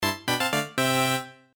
notification_fairy4.mp3